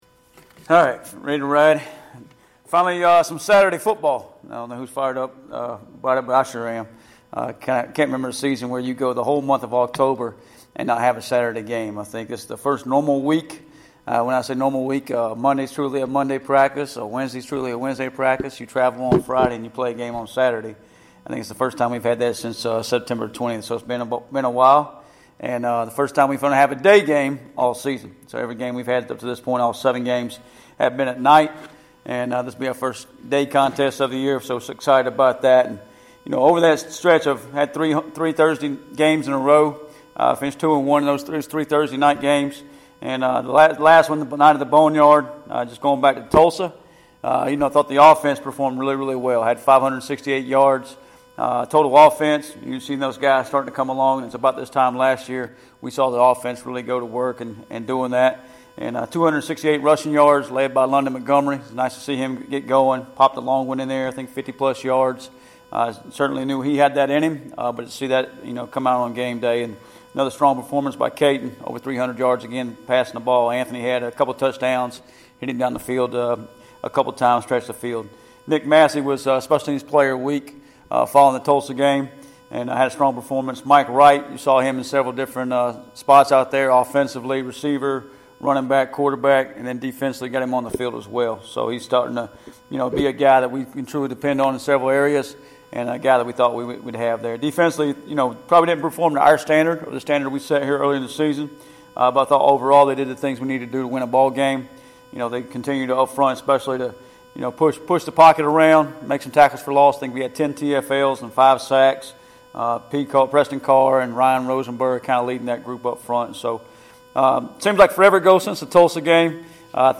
Weekly Press Conference- Temple